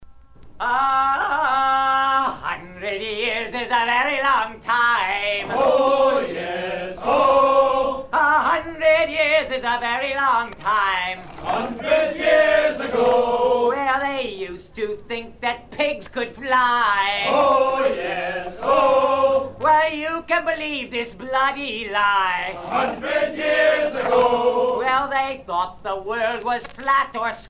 maritimes